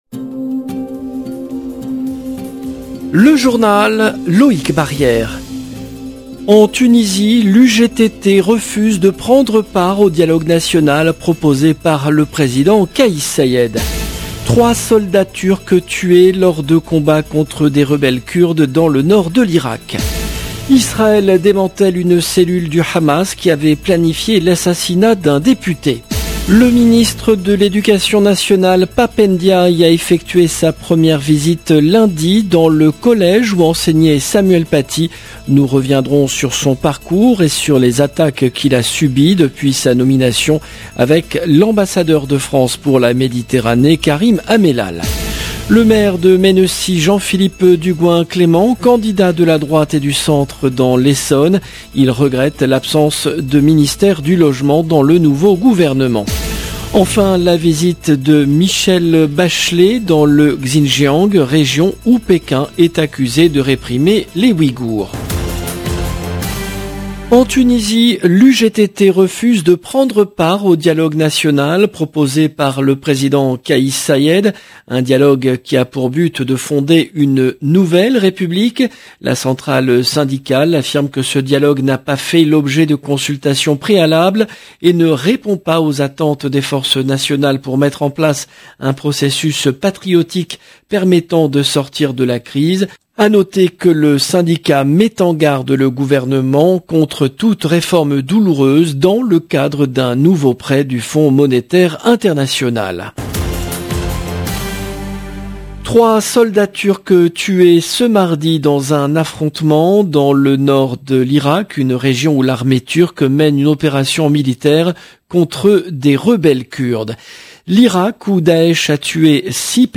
Journal